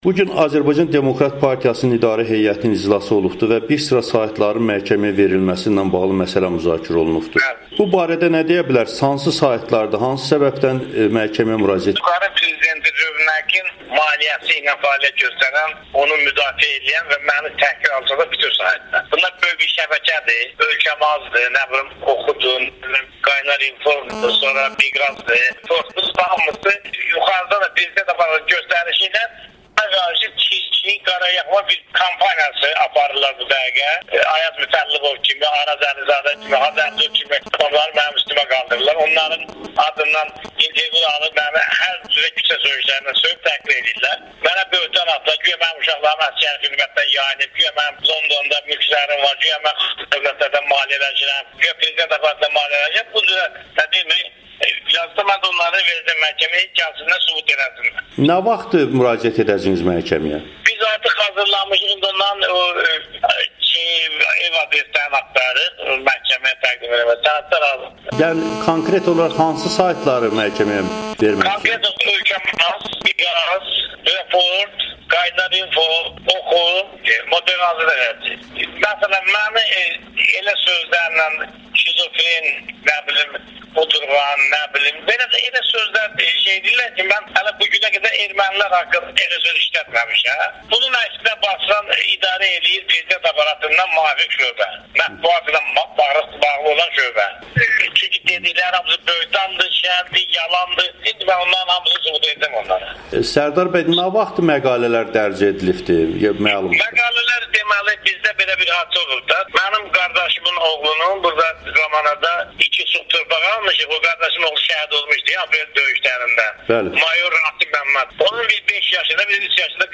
ADP sədri Sərdar Cəlaloğlunun Amerikanın Səsinə müsahibəsi